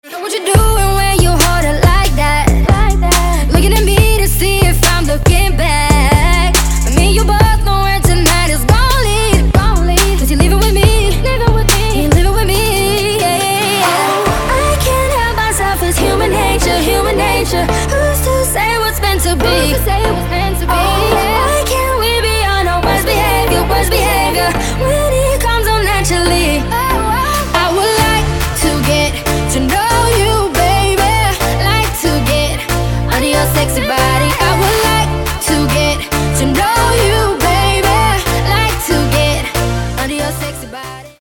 • Качество: 192, Stereo
поп
женский вокал
dance
EDM
vocal